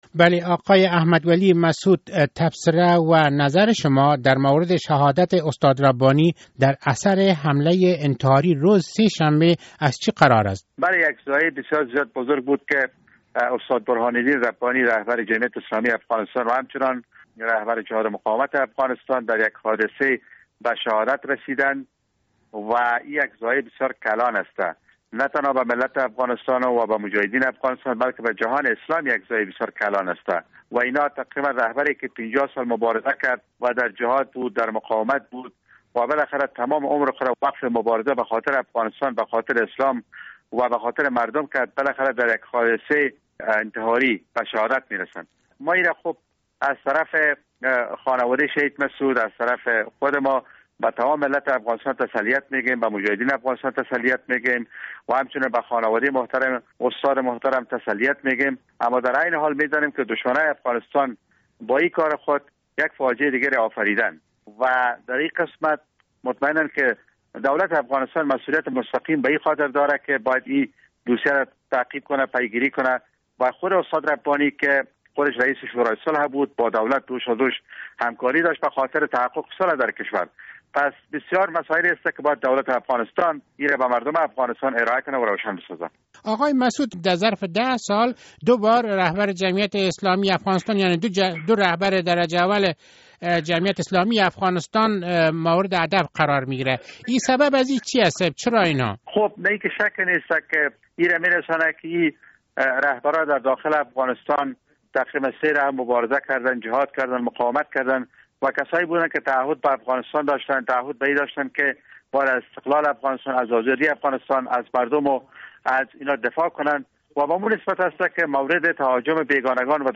مصاحبه با احمد ولی مسعود در باره قتل استاد برهان الدین ربانی